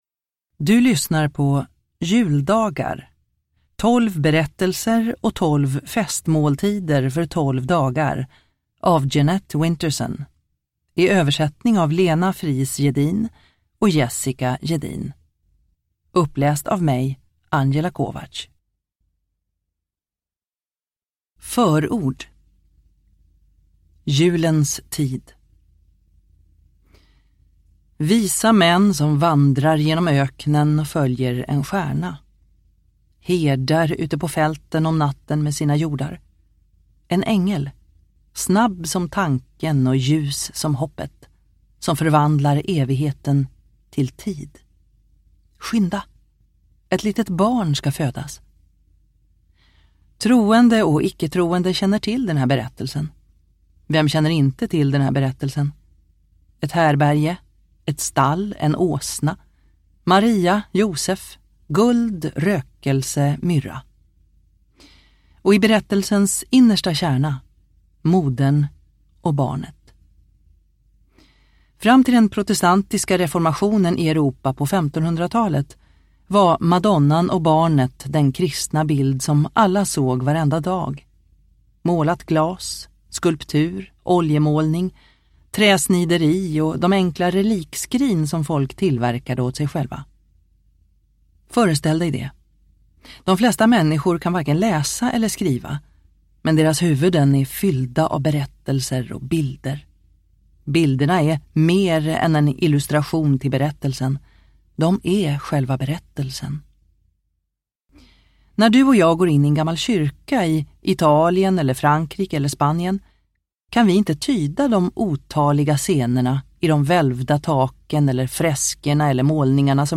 Juldagar : 12 berättelser och 12 festmåltider för 12 dagar – Ljudbok – Laddas ner